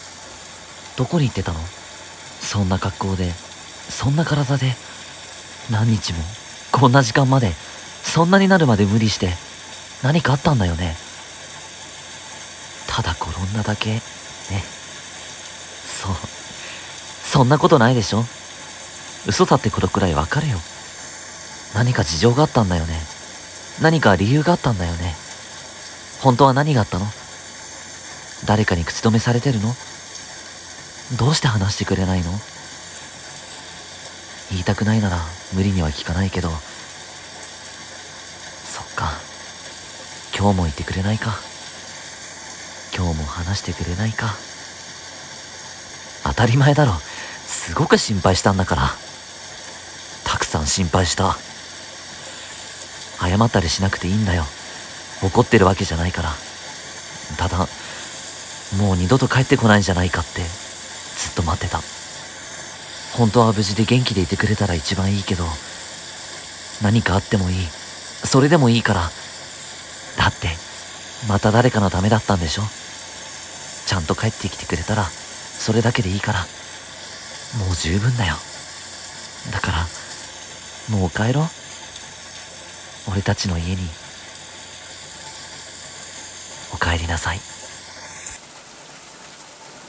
【一人声劇】帰るべき居場所